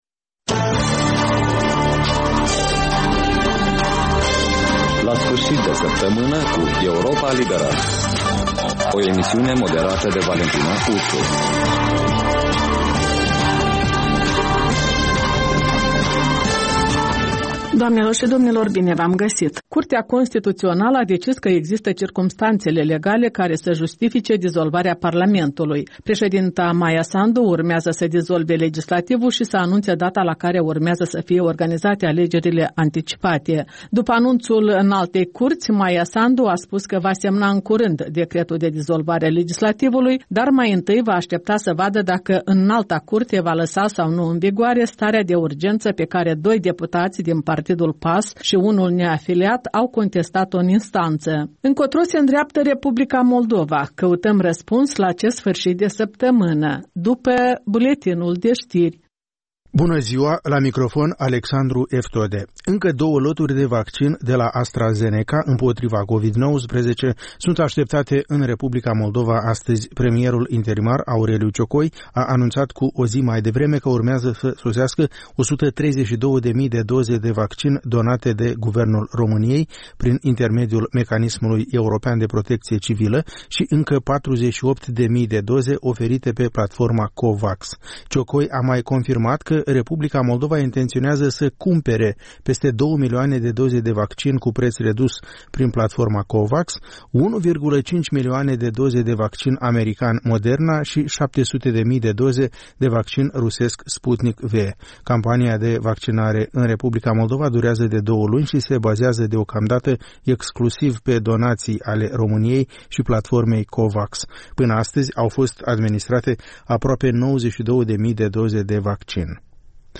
O emisiune în reluare cu un buletin de ştiri actualizat, emisiunea se poate asculta şi pe unde scurte